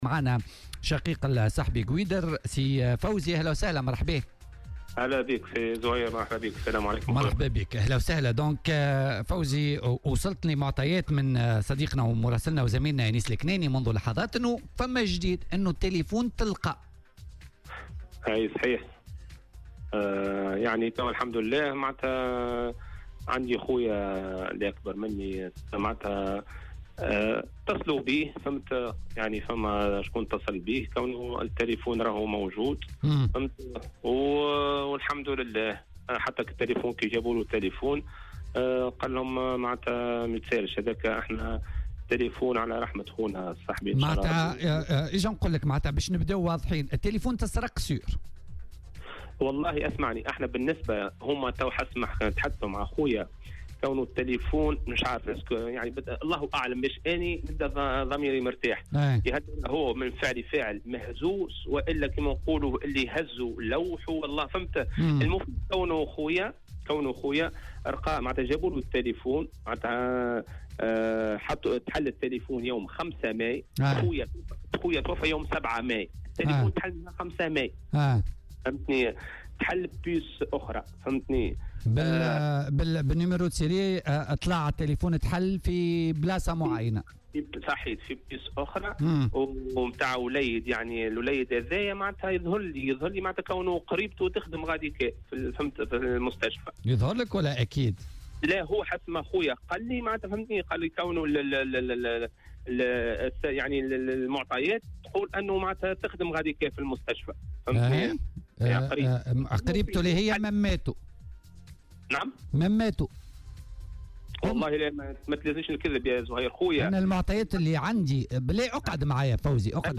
وأضاف في مداخلة له اليوم على "الجوهرة أف أم" أن العائلة تنازلت عن الهاتف مقابل إعادة بطاقة الذاكرة التي تحتوي على صور شقيقهم المتوفي، دون تقديم أي شكاية في الغرض.